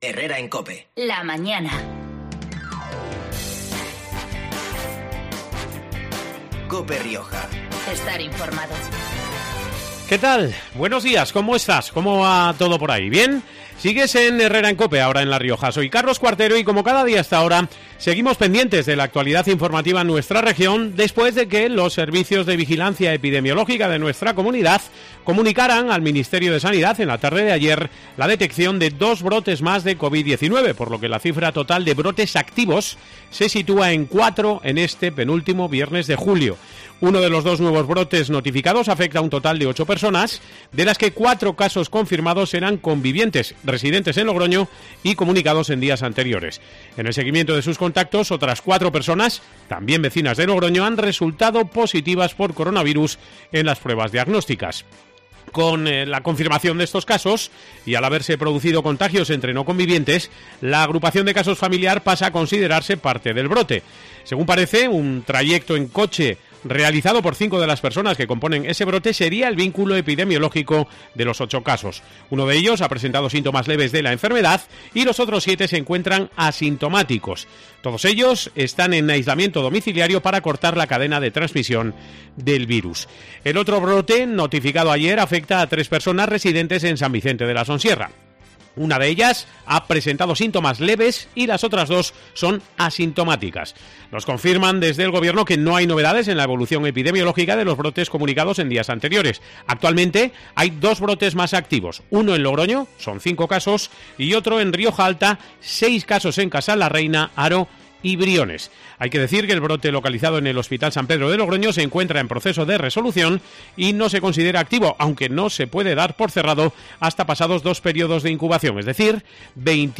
Redacción digital Madrid - Publicado el 24 jul 2020, 12:36 - Actualizado 24 jul 2020, 12:45 1 min lectura Facebook Twitter Whatsapp Telegram Enviar por email Copiar enlace La alcaldesa de Agoncillo , Encarna Fuertes , ha promocionado este mediodía en COPE Rioja los principales reclamos turísticos de su localidad, que tiene en el castillo de Aguas Mansas uno de sus principales recursos.